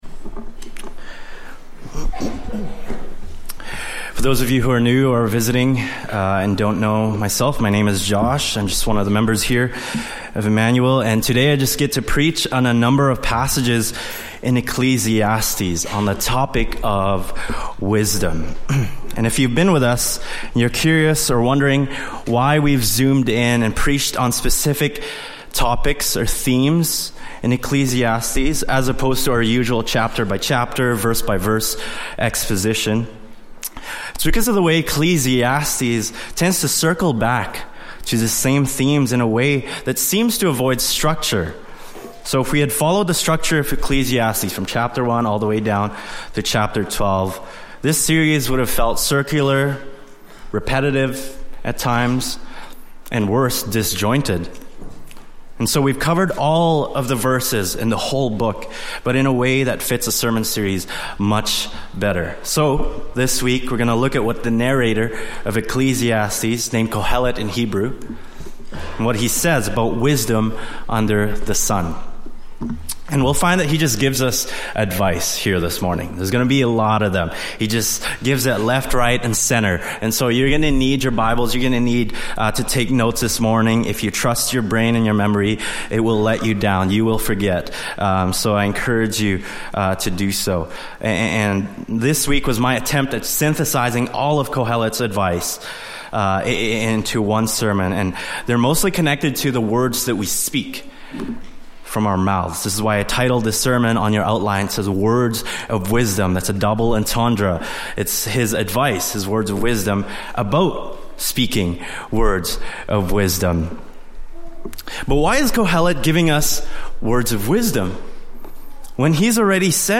Service Type: Latest Sermon